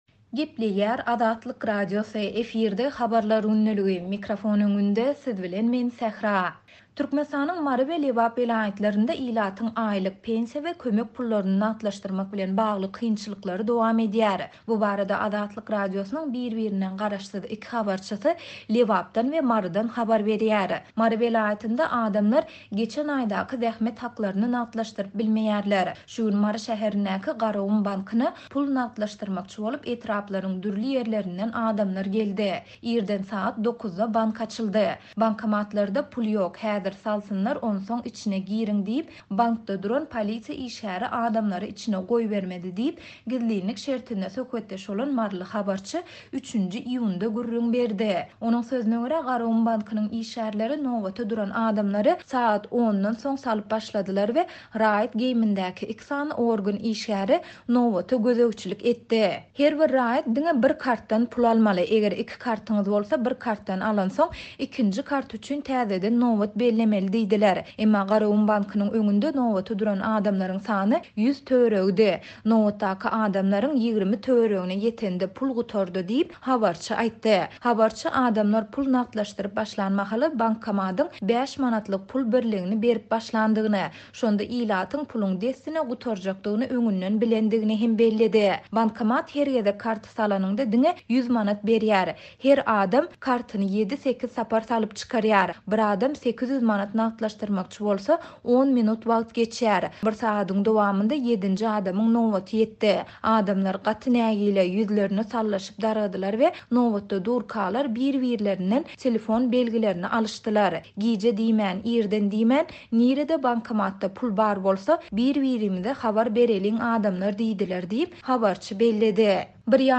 Türkmenistanyň Mary we Lebap welaýatlarynda ilatyň aýlyk, pensiýa we kömek pullaryny nagtlaşdyrmak bilen bagly kynçylyklary dowam edýär. Bu barada Azatlyk Radiosynyň biri-birinden garaşsyz iki habarçysy Lebapdan we Marydan habar berýär.